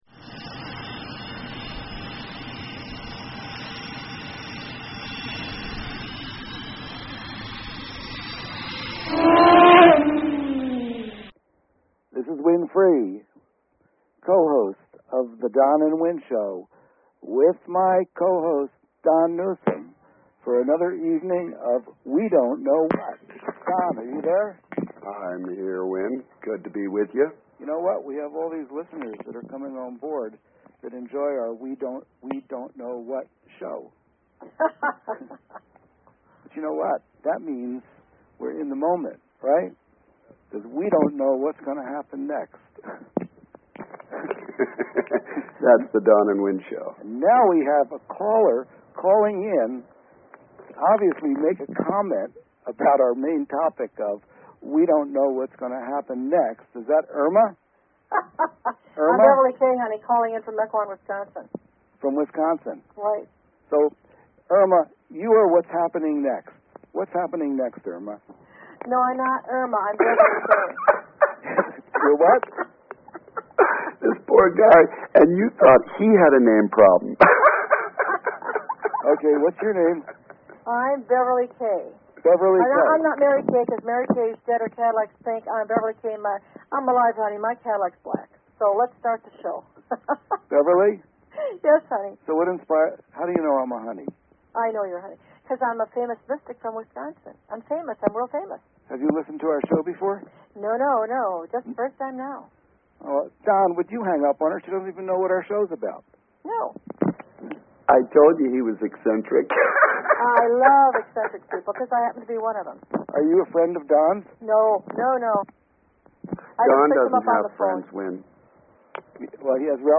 Talk Show Episode
Drive time radio with a metaphysical slant.